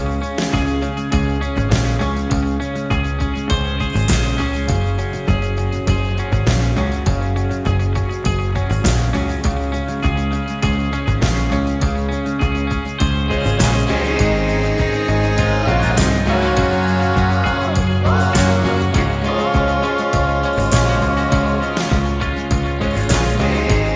no Backing Vocals Indie / Alternative 4:24 Buy £1.50